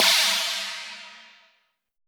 FLANGECHINA.wav